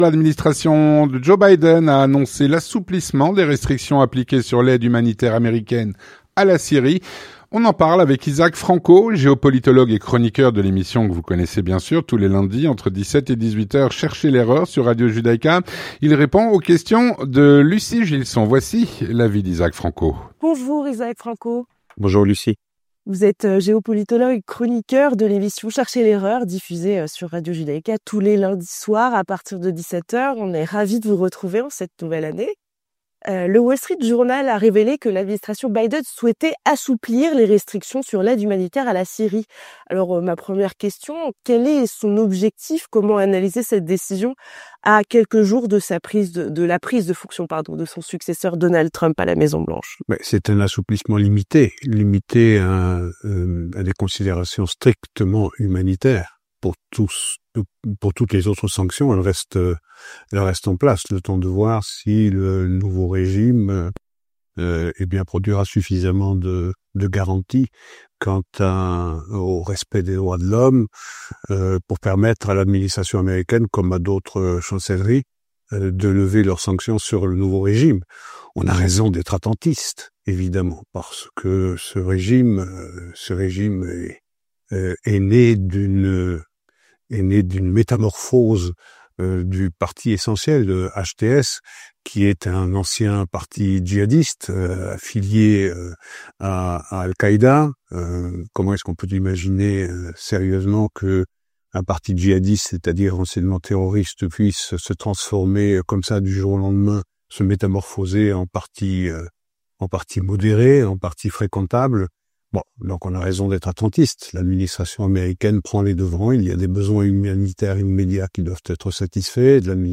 L'entretien du 18H - L’administration Biden a annoncé l’assouplissement des restrictions envers la Syrie.